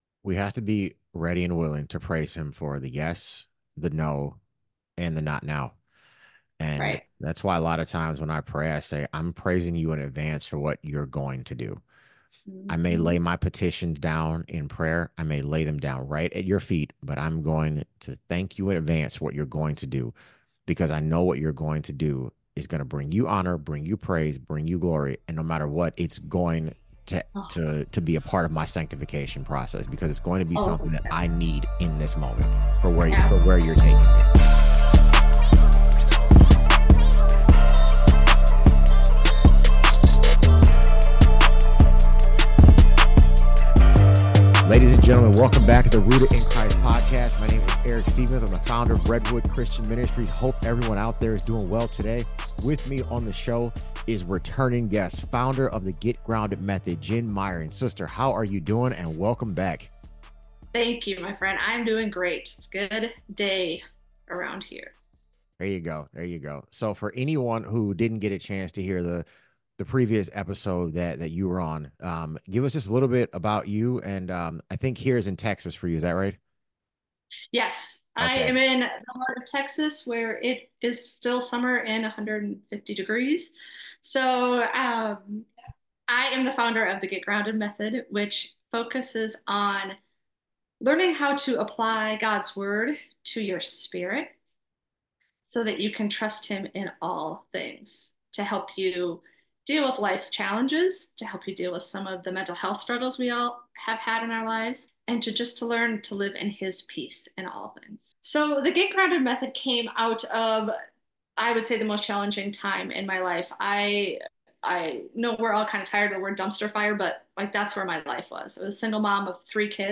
In this episode of The Rooted in Christ podcast we're sitting down for a conversation with a returning guest,